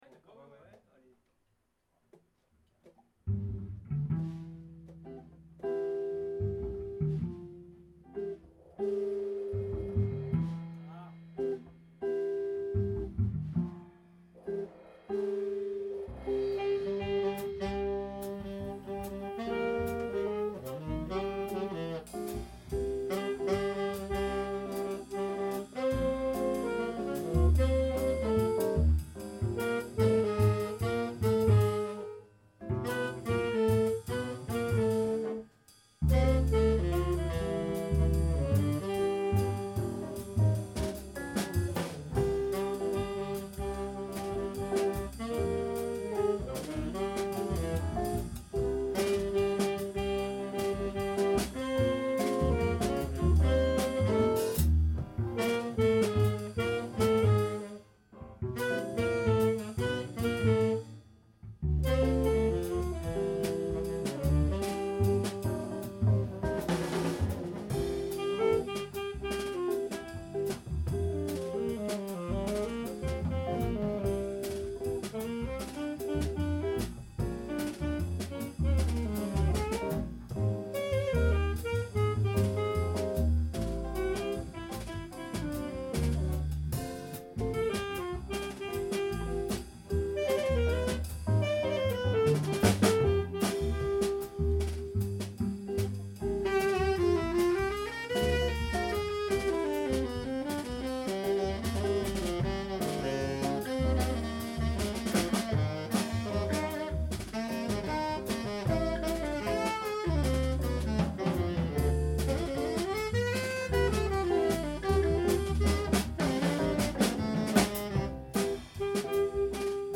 Mirajazz groupe de jazz amateur
Deux nouveaux morceaux joués en répétition:(cliquer)